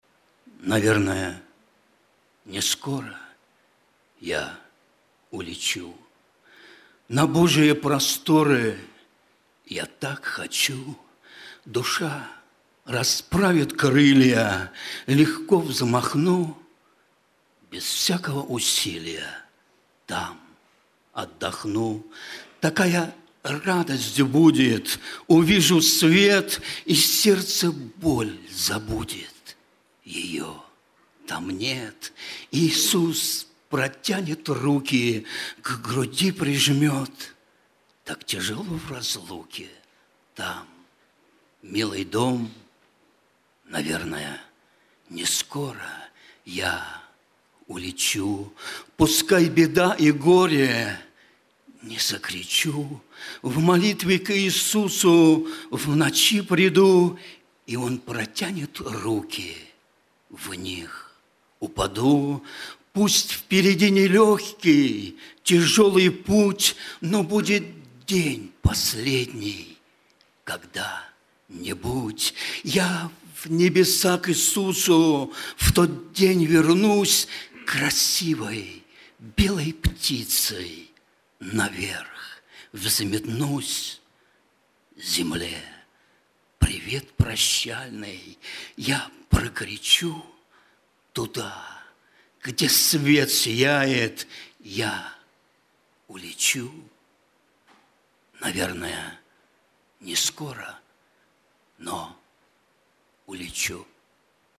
Богослужение 27.10.2024
Стихотворение